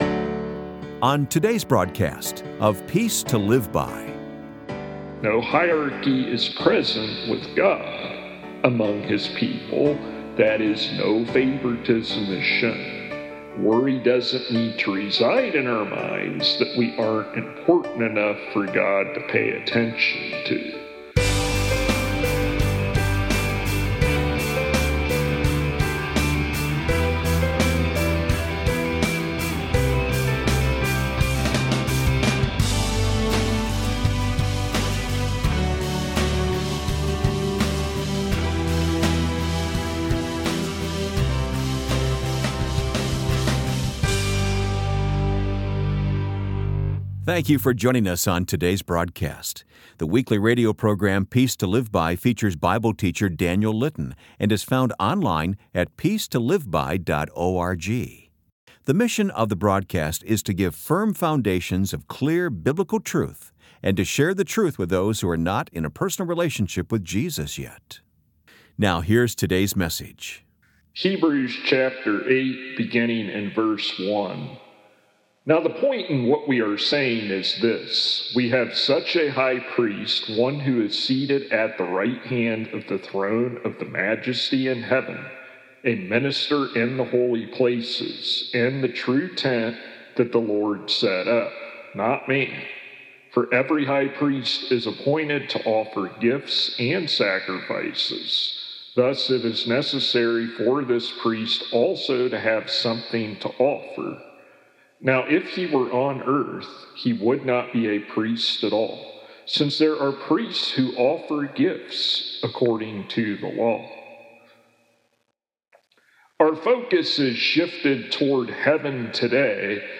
[Transcript represents full sermon's text]